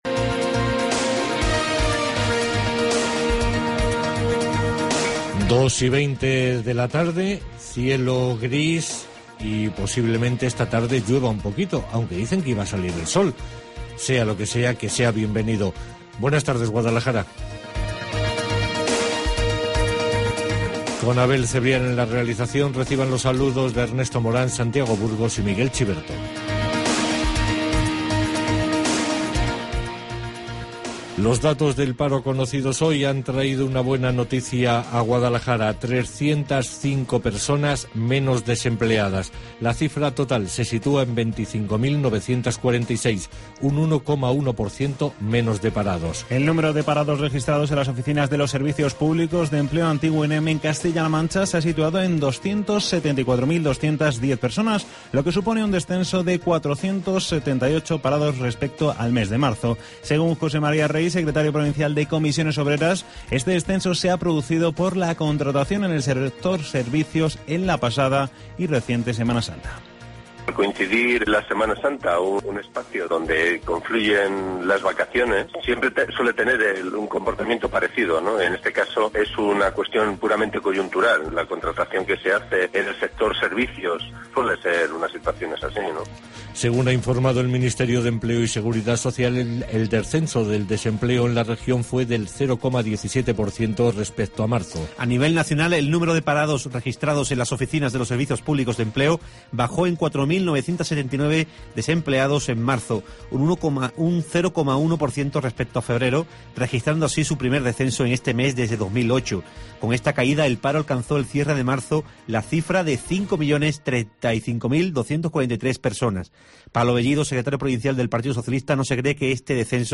Informativo Guadalajara 2 de abril